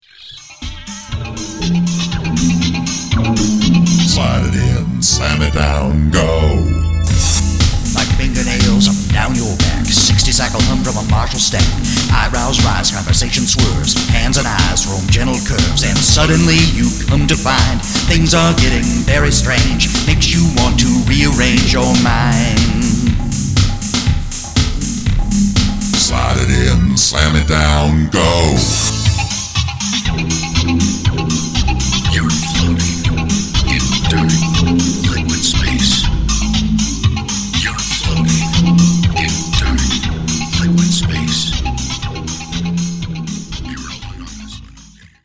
like a song by a DJ